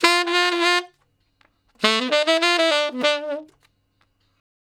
066 Ten Sax Straight (D) 04.wav